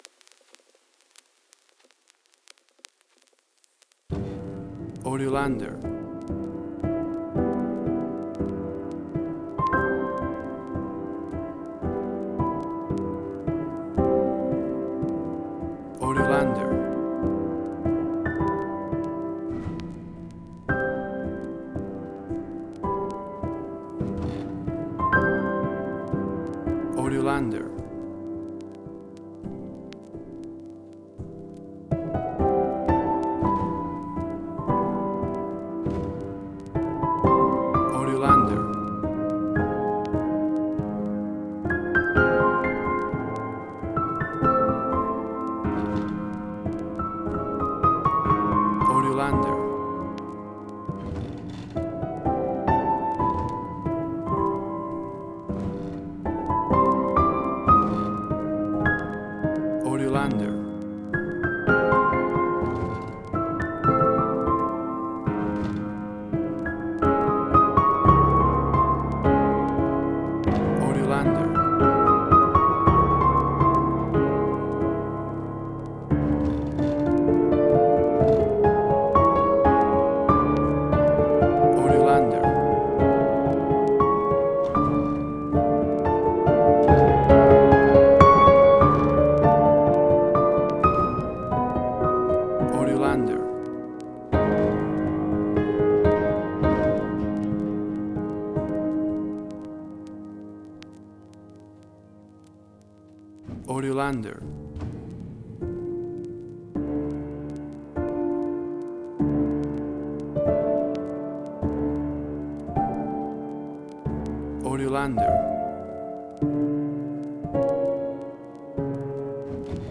Post-apocalyptic out of tune piano.